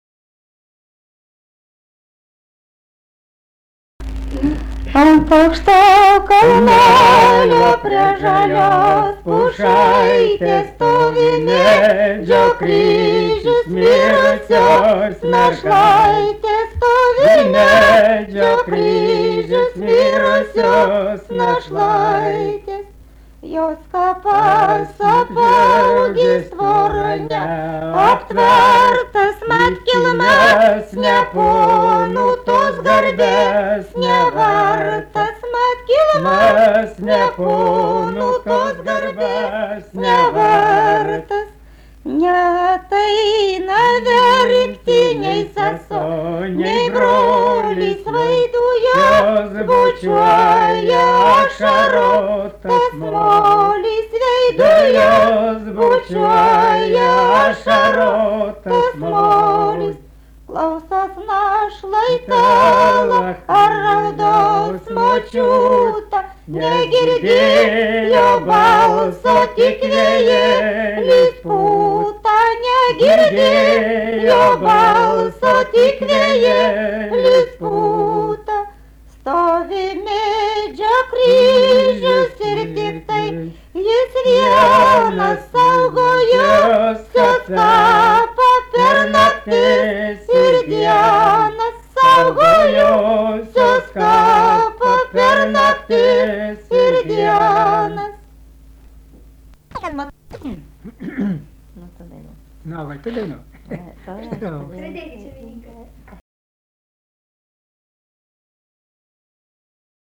daina
Papiliai
vokalinis